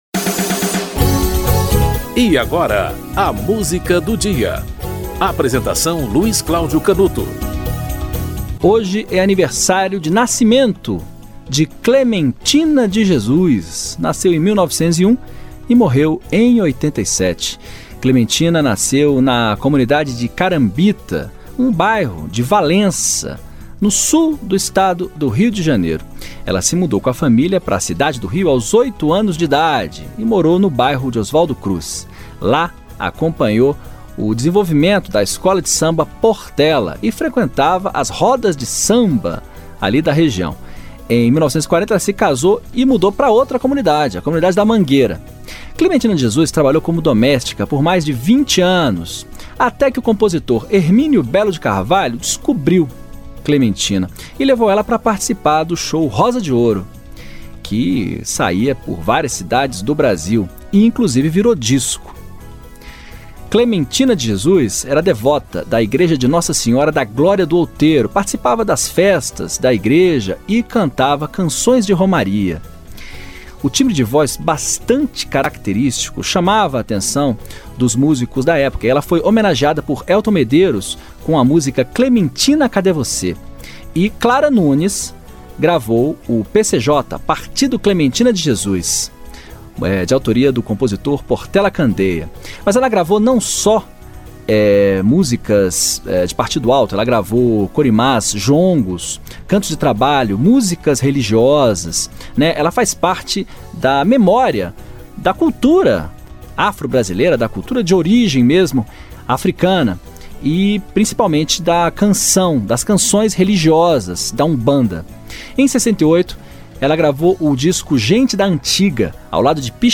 Clementina de Jesus - Fui Pedir às Almas Santas (música tradicional)
Produção e apresentação: